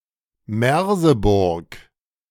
Merseburg (German pronunciation: [ˈmɛʁzəbʊʁk]